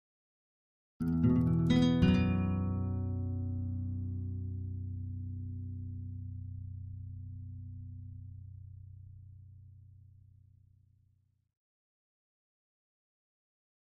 Acoustic Guitar - Acoustic Guitar Arpeggio 1 - E Minor